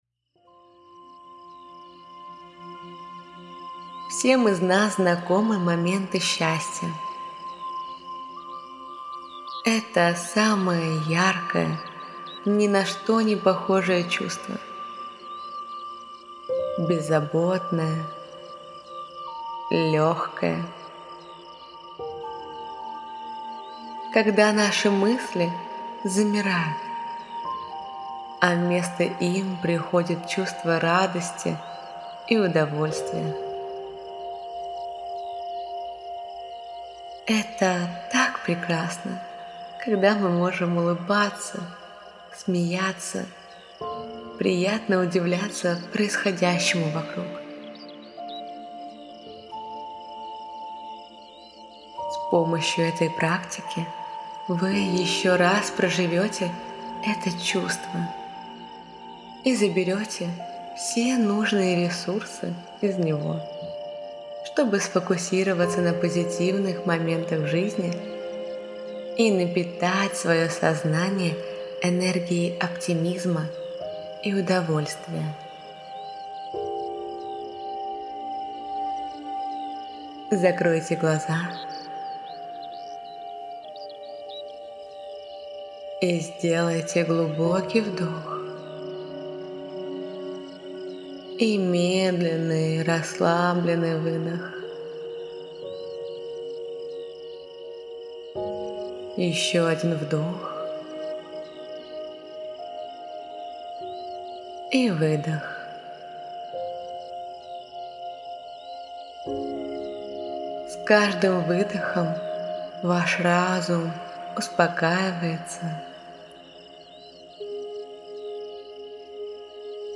Уникальные аудио уроки и практики по медитации для привлечения счастливых событий